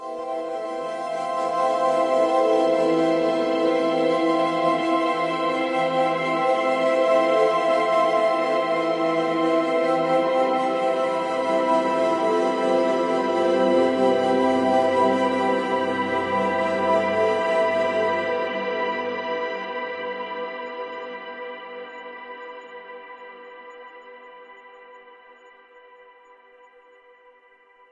环境和深沉的低音垫 " Cminor7 叠加弓弦 1
描述：采用Volca FM和Microbrute录制，采用DOD G10机架式，Digitech RP80和Ableton处理
标签： 声景 环境 样品 空间 低音
声道立体声